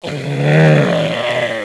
Index of /svencoop/sound/paranoia/zombie
zo_pain1.wav